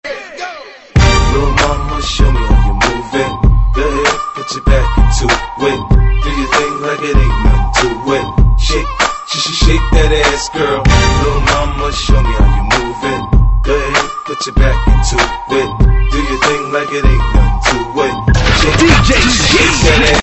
Рэп, Хип-Хоп, R'n'B [75]